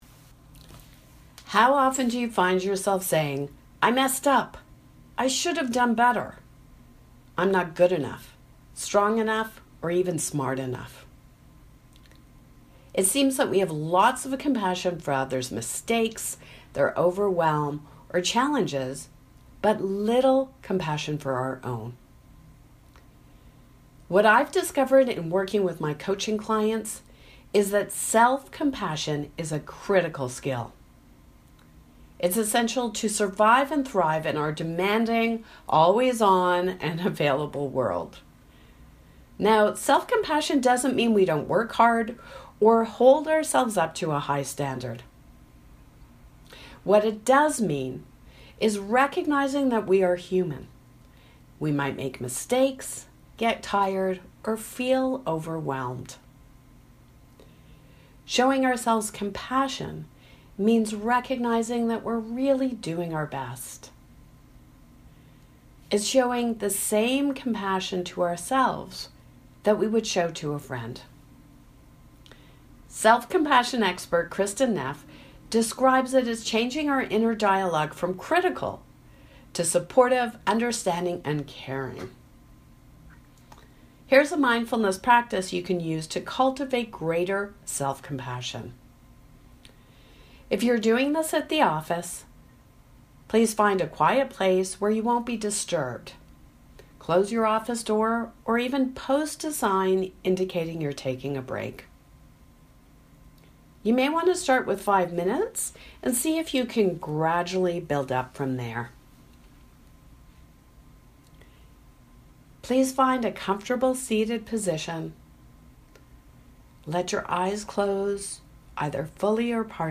Guided Mindfulness
Self-compassion meditation
Self-compassion-Meditation-audio.mp3